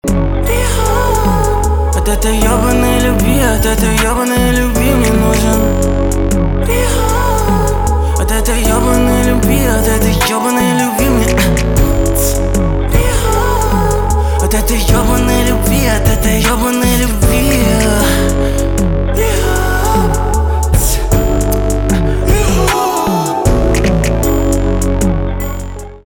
русский рэп
битовые , басы , грустные , печальные